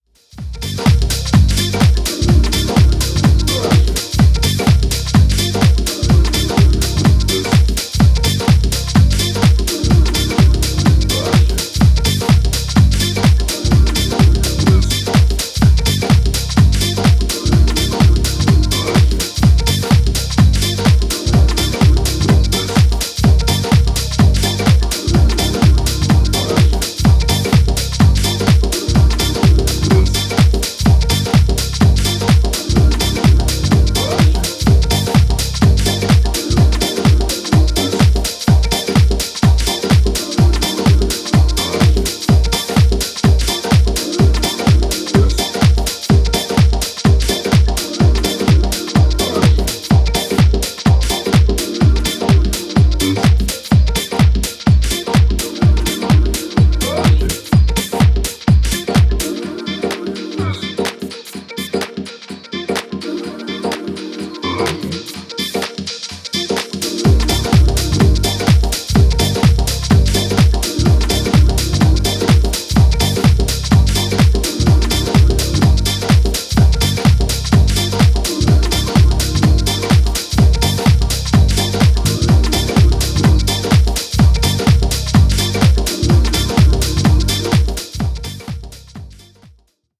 メランコリックで絶え間ないシンセワークが踊るソリッドなディープ・ハウス